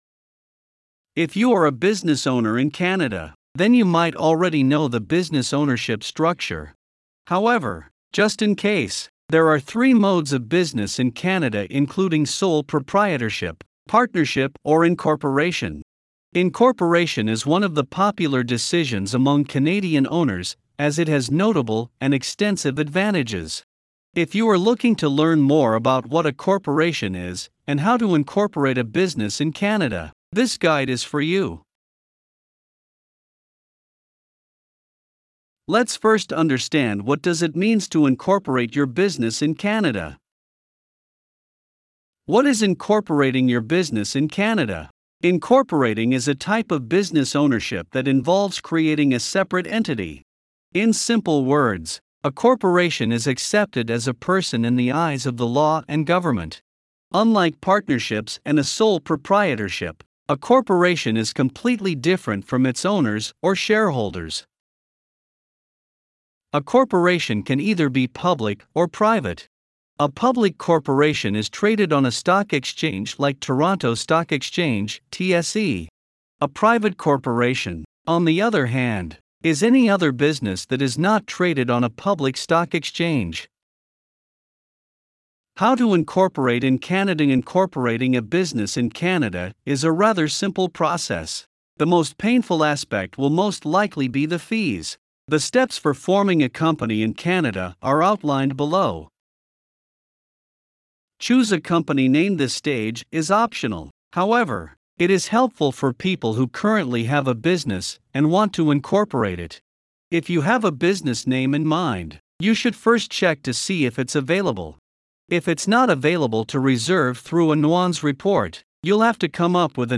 Voiceovers-Voices-by-Listnr_13.mp3